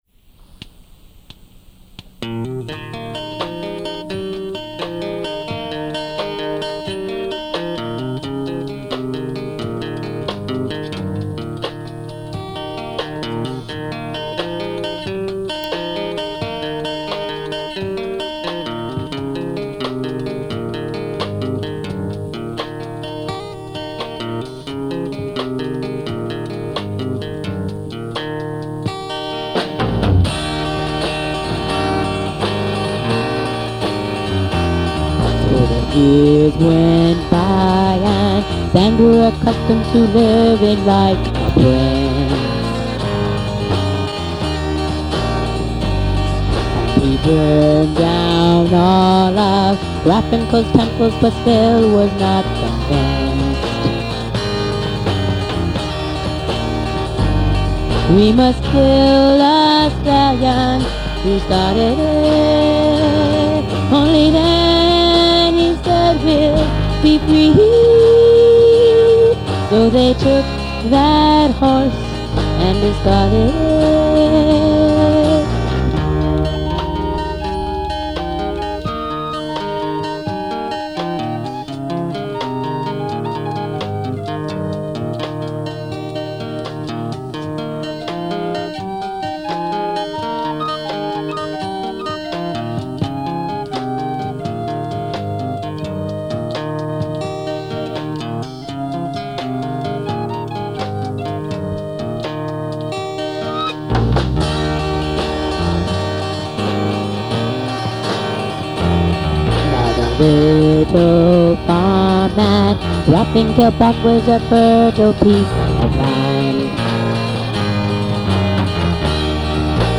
vocals
drums